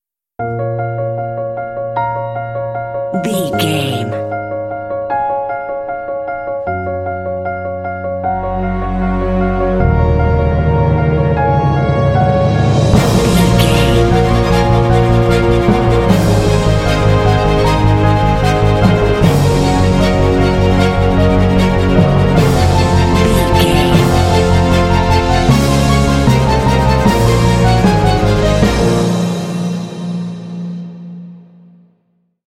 Epic / Action
Fast paced
Aeolian/Minor
B♭
piano
strings
orchestra
cinematic